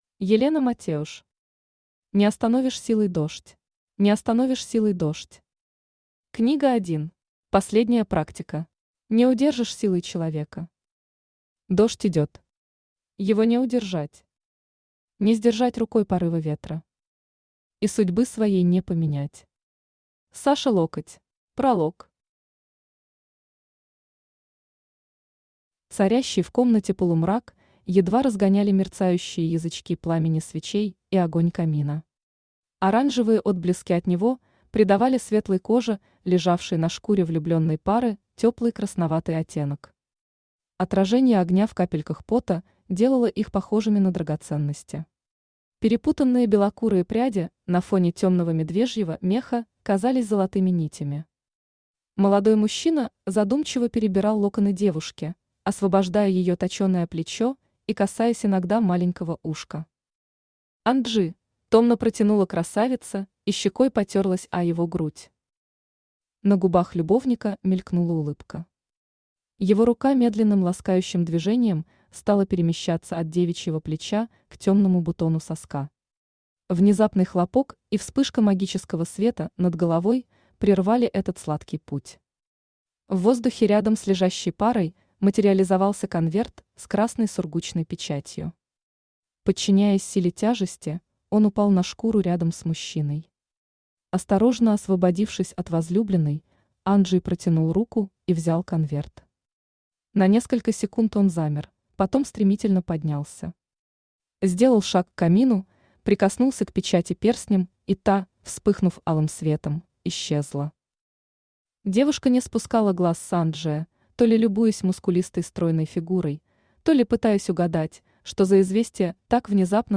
Аудиокнига Не остановишь силой дождь | Библиотека аудиокниг
Aудиокнига Не остановишь силой дождь Автор Елена Матеуш Читает аудиокнигу Авточтец ЛитРес.